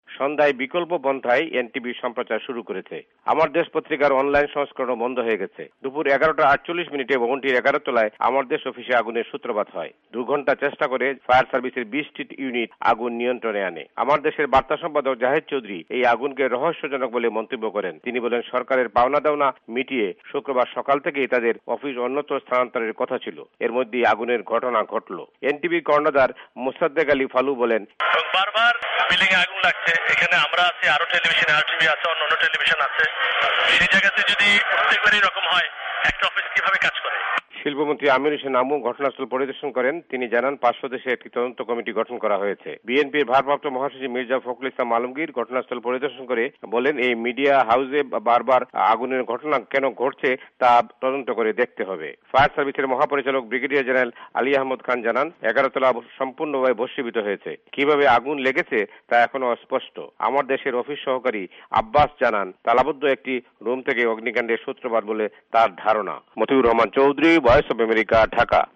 ভয়েস অব আমেরিকার ঢাকা সংবাদদাতাদের রিপোর্ট